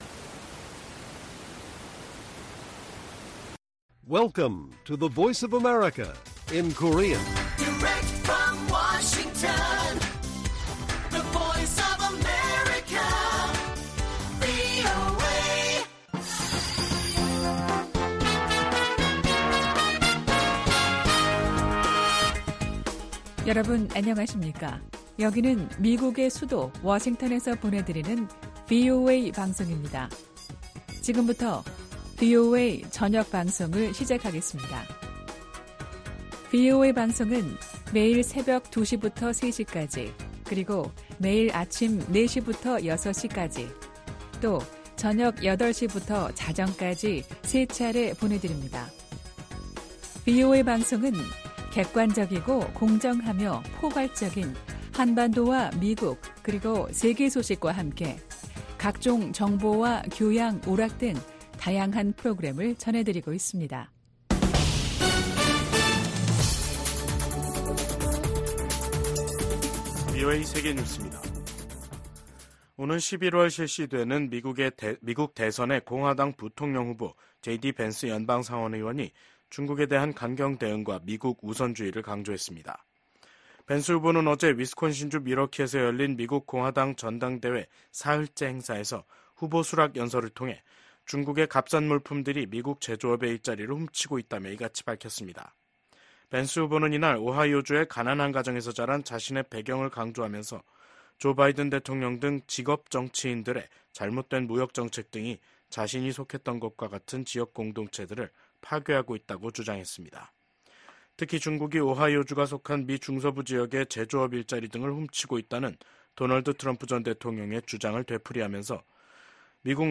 VOA 한국어 간판 뉴스 프로그램 '뉴스 투데이', 2024년 7월 18일 1부 방송입니다. 미 중앙정보국(CIA) 출신의 대북 전문가가 미 연방검찰에 기소됐습니다. 북한이 신종 코로나바이러스 감염증 사태가 마무리되면서 무역 봉쇄를 풀자 달러 환율이 고공행진을 지속하고 있습니다. 최근 북한을 방문한 유엔 식량농업기구 수장이 북한이 농업 발전과 식량 안보에서 큰 성과를 냈다고 주장했습니다.